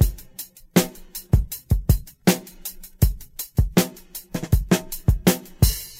106 Bpm Drum Groove F# Key.wav
Free breakbeat - kick tuned to the F# note. Loudest frequency: 2329Hz
.WAV .MP3 .OGG 0:00 / 0:06 Type Wav Duration 0:06 Size 1,01 MB Samplerate 44100 Hz Bitdepth 16 Channels Stereo Free breakbeat - kick tuned to the F# note.
106-bpm-drum-groove-f-sharp-key-UBT.ogg